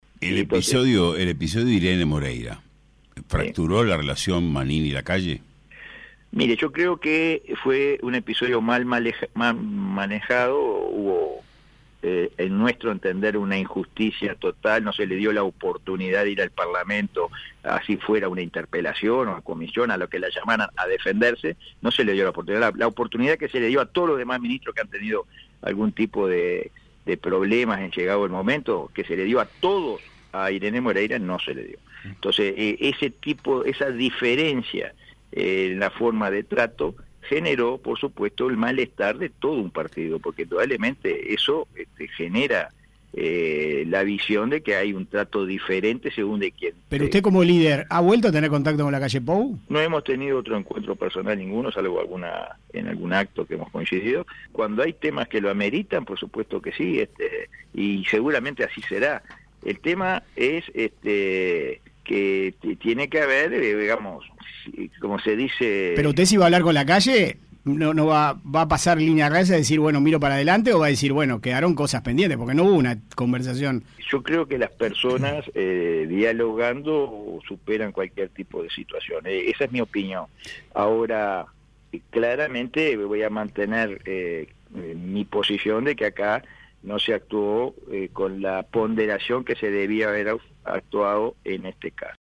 El senador y líder de Cabildo Abierto, Guido Manini Rios, reconoció en entrevista con 970 Noticias que luego del episodio que desenadenó la renuncia de la ahora exministra de Vivienda y pareja del senador, Irene Moreira, el cabildante no ha vuelto a hablar con el mandatario.